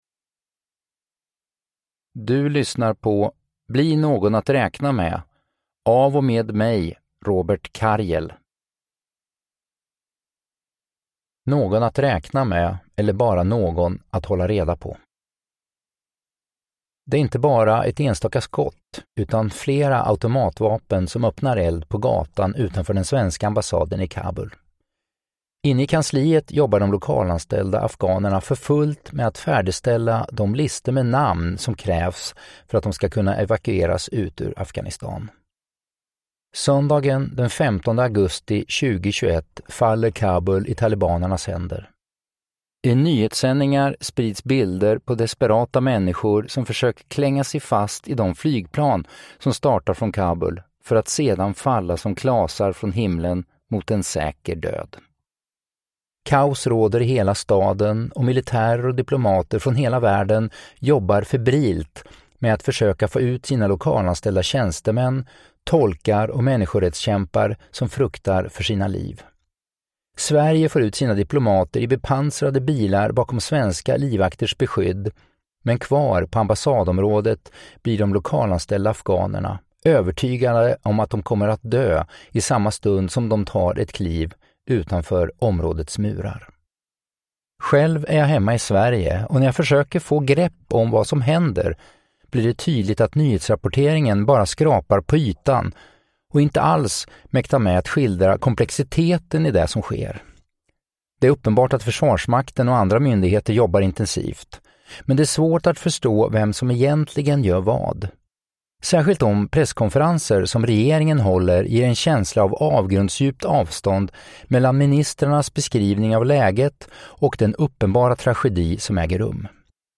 Uppläsare: Robert Karjel
• Ljudbok